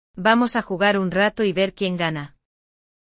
No episódio de hoje, vamos mergulhar em um diálogo entre dois amigos que decidem jogar um novo videogame para se distrair.
O episódio traz uma conversa simples entre amigos jogando videogame, com explicações detalhadas para facilitar o aprendizado.